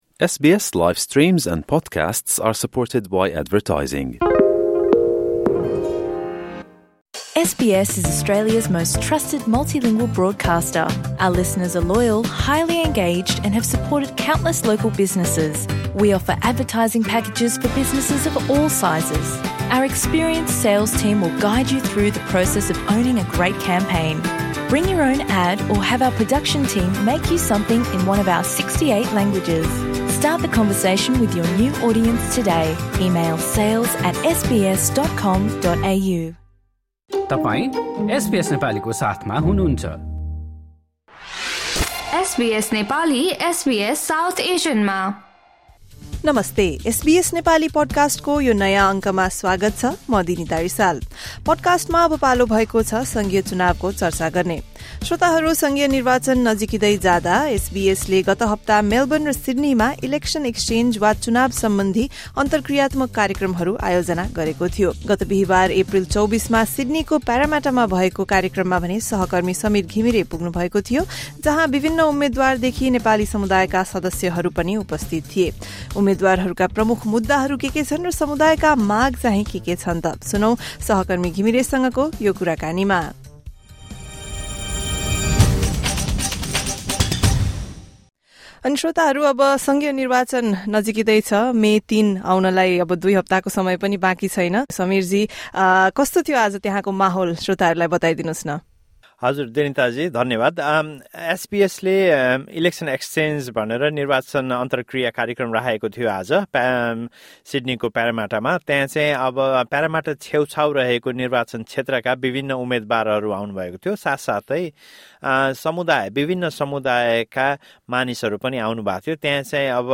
सङ्घीय निर्वाचनको मिति नजिकिँदै जाँदा अगिल्लो सप्ताह एसबीएसले मेलबर्न र सिड्नीमा ‘इलेक्सन एक्सचेञ्ज’ आयोजना गरेको थियो।
Nepali community members speaking at the SBS Election Exchange in Parramatta on Thursday, 24 April 2025.